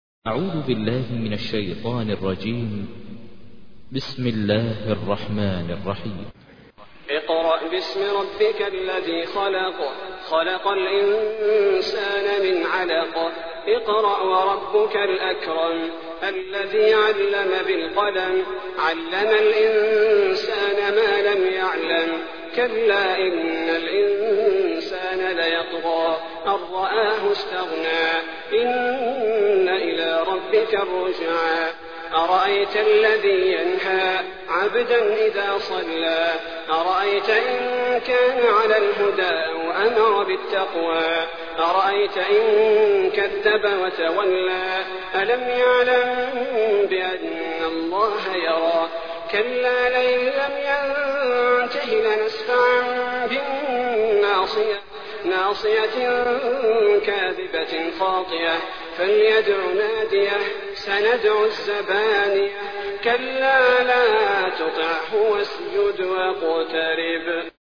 تحميل : 96. سورة العلق / القارئ ماهر المعيقلي / القرآن الكريم / موقع يا حسين